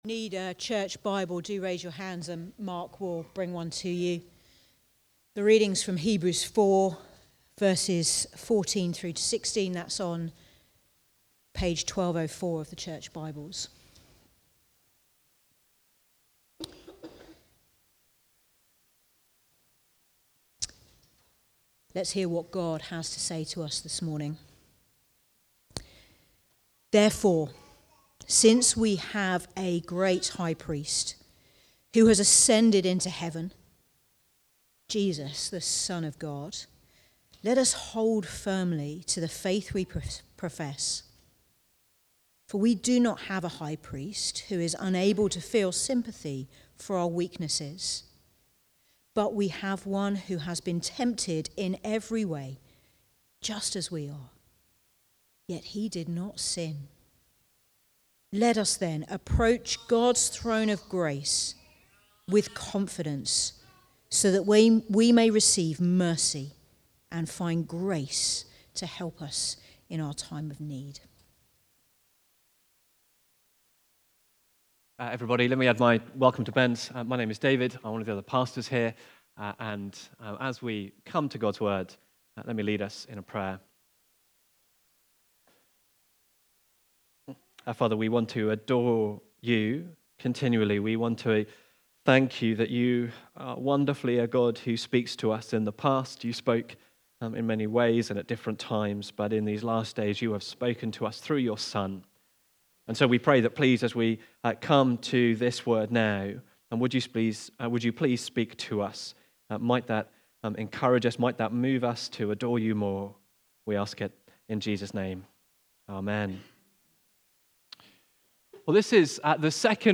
Preaching
Access to Draw Near (Hebrews 4:14-16) from the series A Vision for 2025. Recorded at Woodstock Road Baptist Church on 12 January 2025.